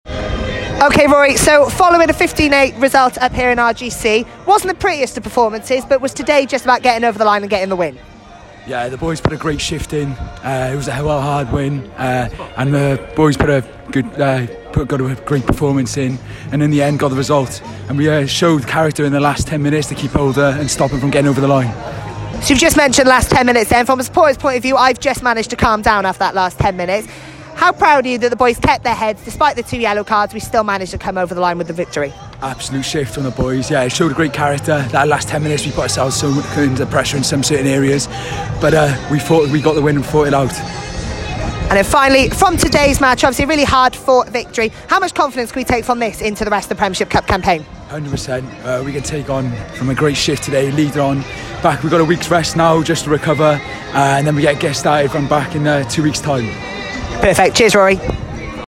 Post Match Interviews